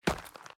walk.ogg